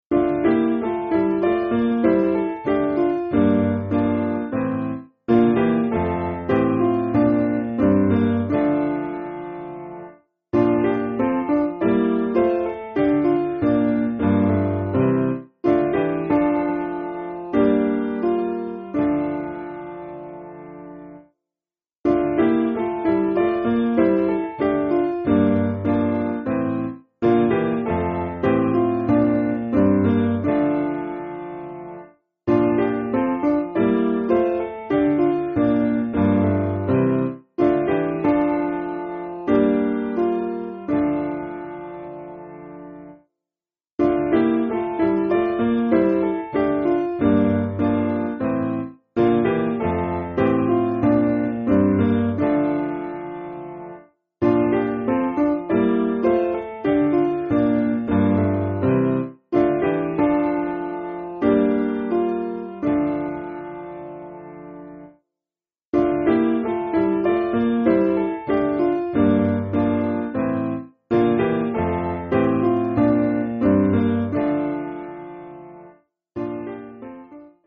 Simple Piano
(CM)   6/Dm